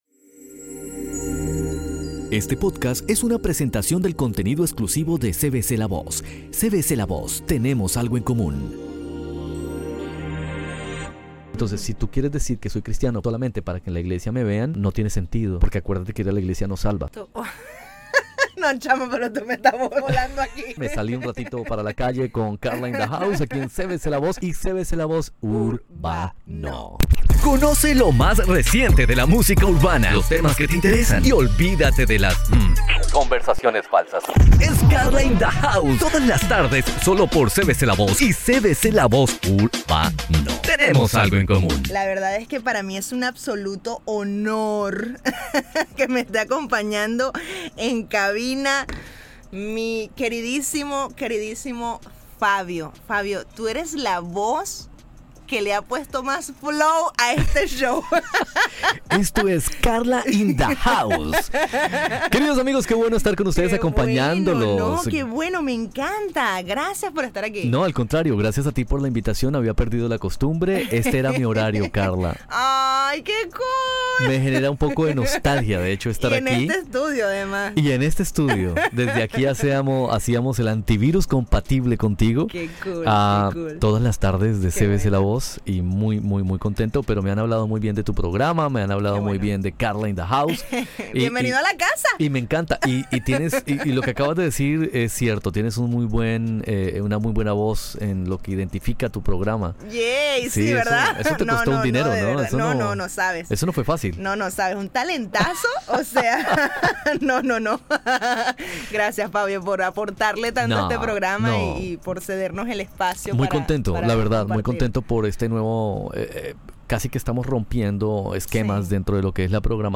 Si eres cristiano TIENES QUE OÍR ESTA CONVERSACIÓN… quizás te den ganas de compartirla.